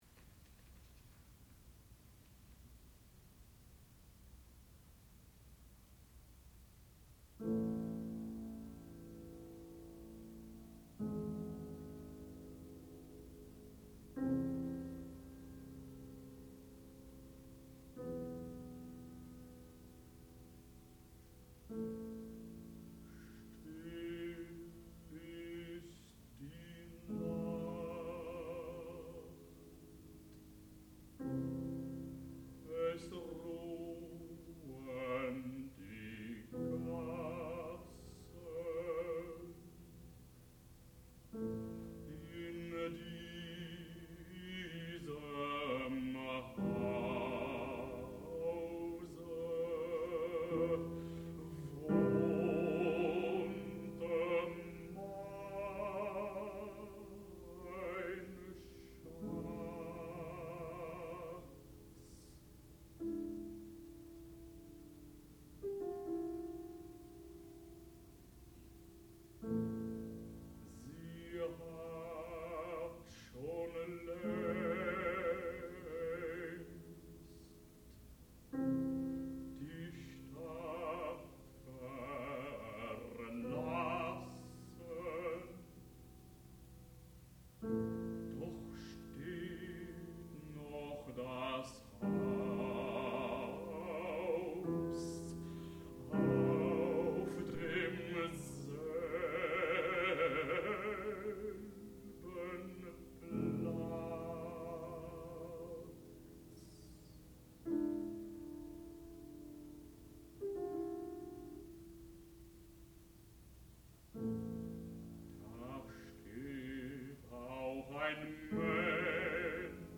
sound recording-musical
classical music
Knut Skram, baritone and Eva Knardahl, piano (performer).